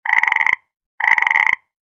Frog-croak-sound-effect.mp3